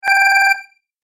دانلود آهنگ هشدار 15 از افکت صوتی اشیاء
جلوه های صوتی
دانلود صدای هشدار 15 از ساعد نیوز با لینک مستقیم و کیفیت بالا